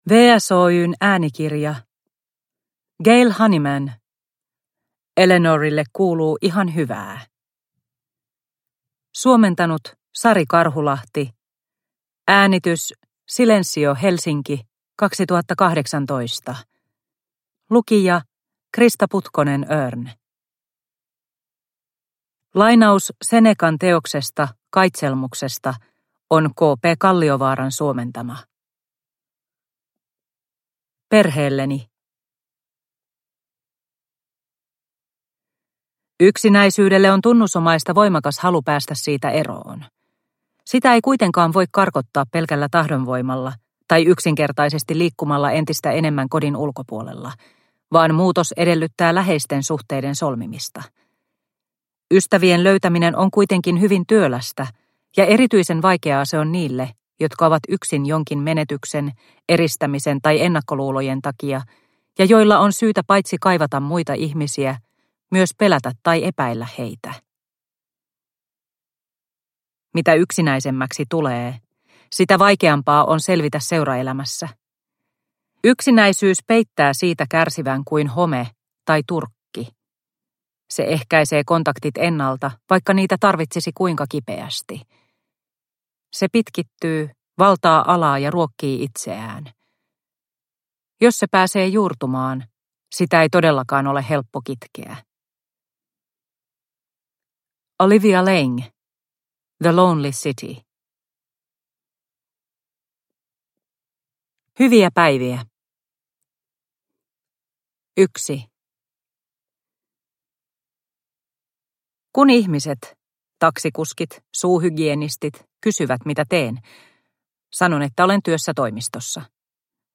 Eleanorille kuuluu ihan hyvää – Ljudbok – Laddas ner